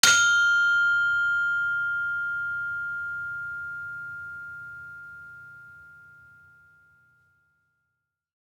Saron-2-F5-f.wav